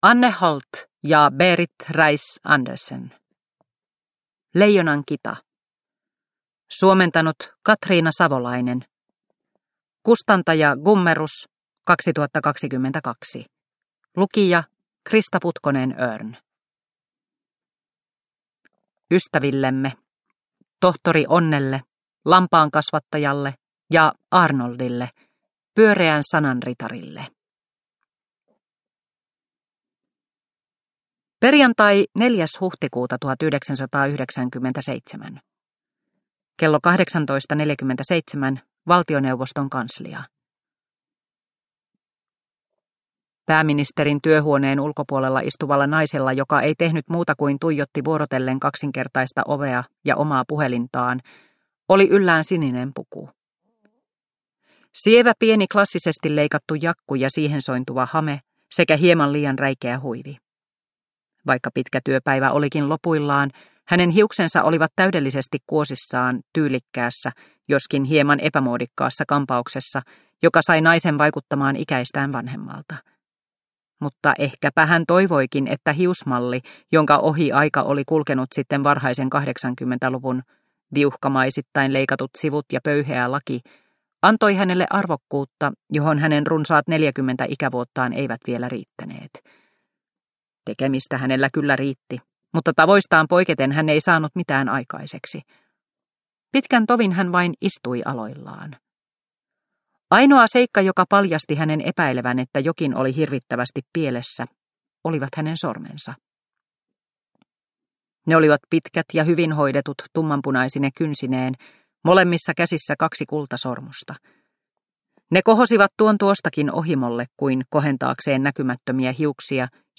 Leijonan kita – Ljudbok – Laddas ner